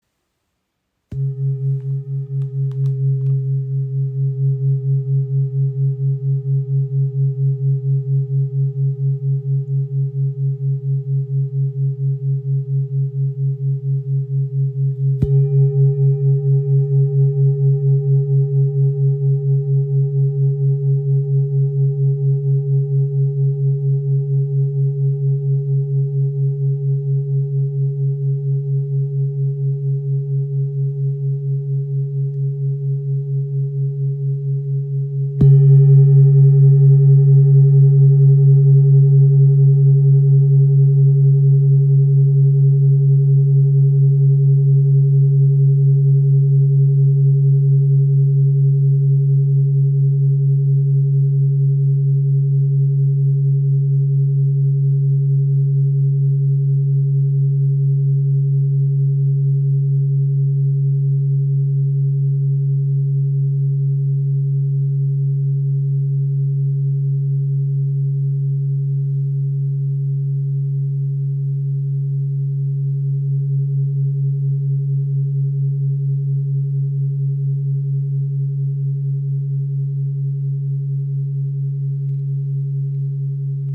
• Icon Inklusive passendem rotem Filzschlägel
• Icon Zentrierender Klang im Ton ~ C# | Planetenton Venus (138,59 Hz) | 1832 g.
Ihr obertonreicher Klang im Ton ~ C# ist klar und zentrierend.
Klangschale mit Medizin Buddha | ø 25 cm | Ton C# | Venus
Diese Schale schwingt sehr lange.